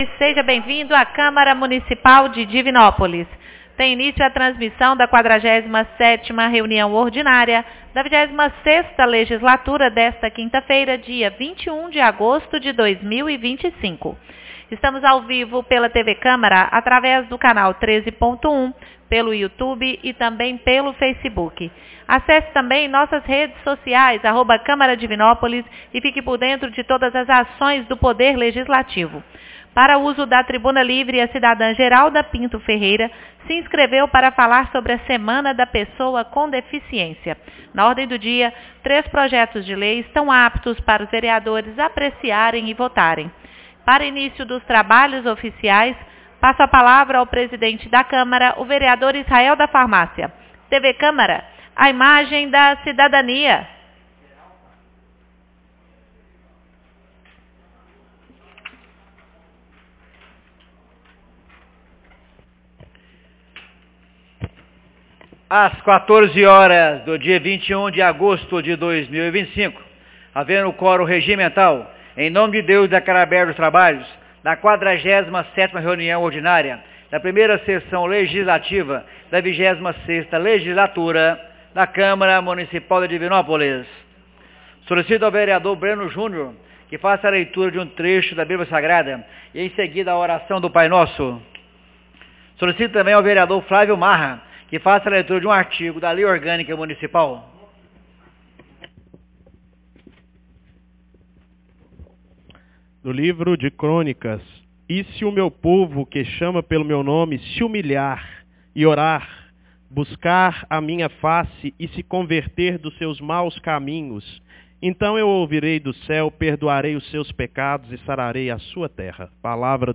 47ª Reunião Ordinaria 21 de agosto de 2025